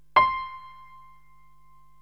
PIANO 0016.wav